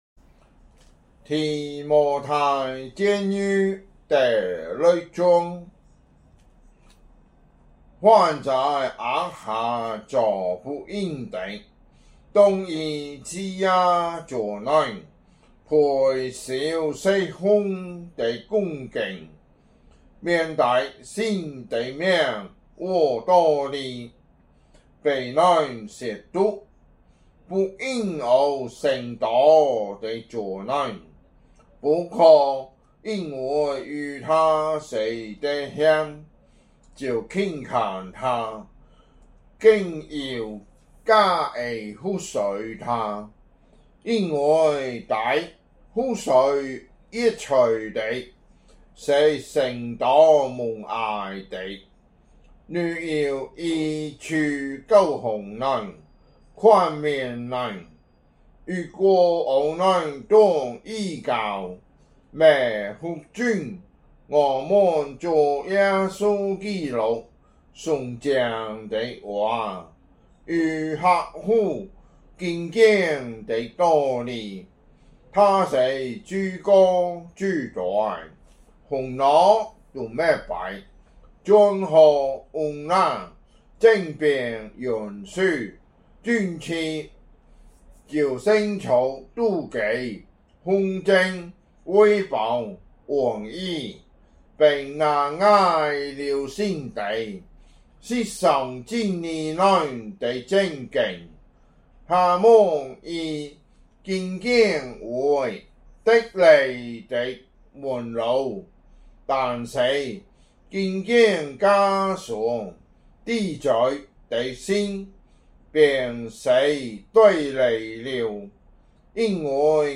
福州話有聲聖經 提摩太前書 6章